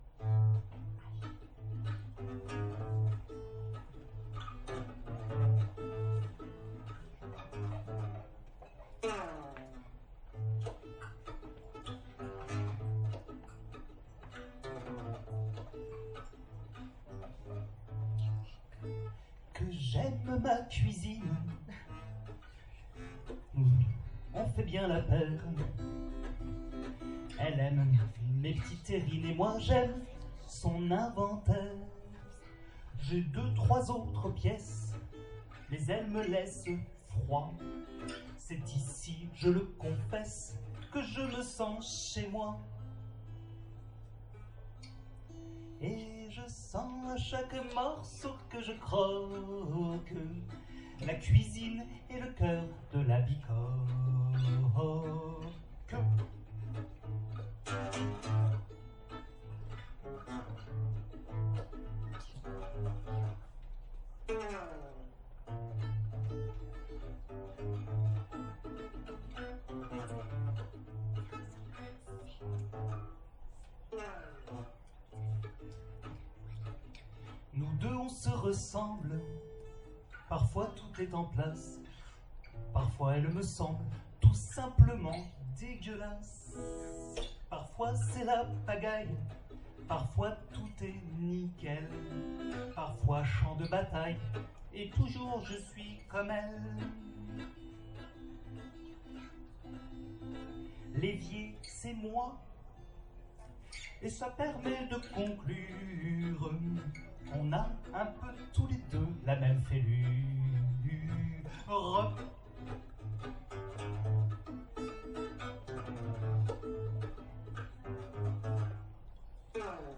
leçon de virtuosité guitaristique et vocalistique